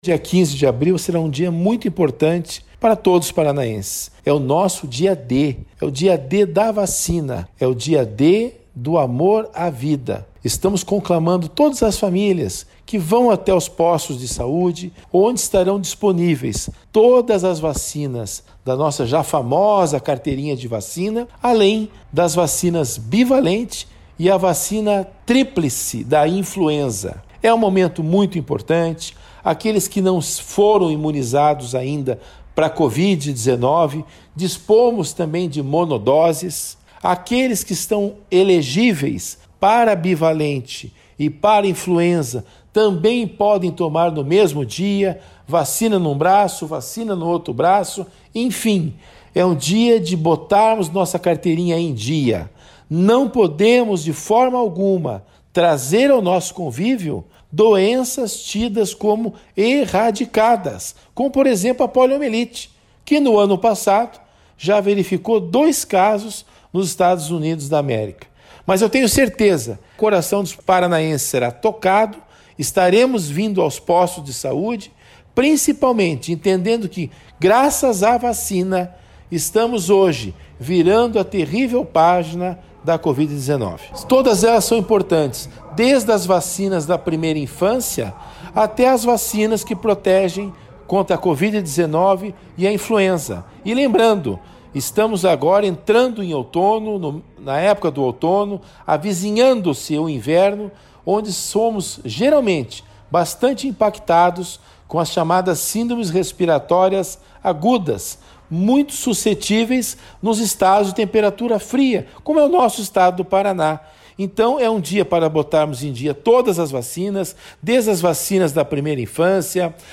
Sonora do secretário da Saúde, César Neves, sobre o primeiro Dia D de Vacinação do Paraná de 2023